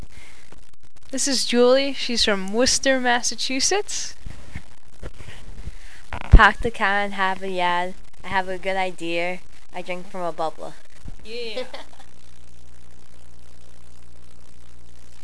New England Dialect: Boston
Boston Accent-Click to Listen